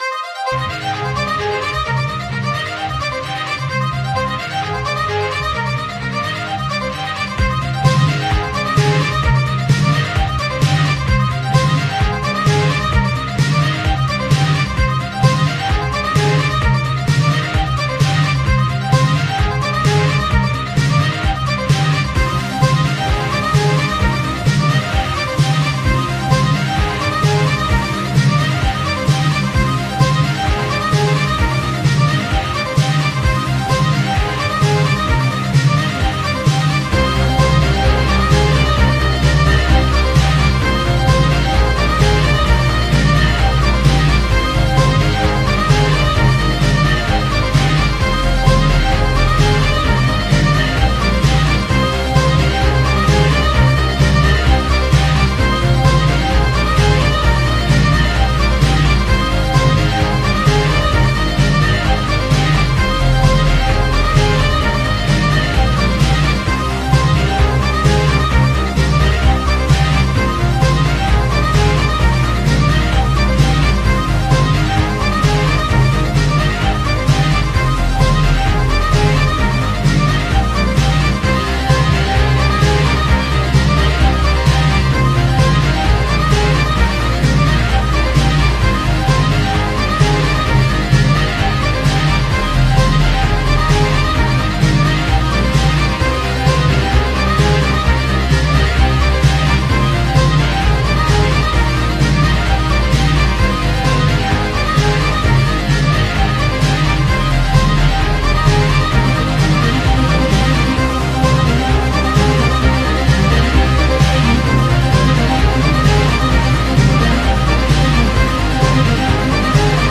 похож на готический стиль
Настоящий Симфо Пауер Метал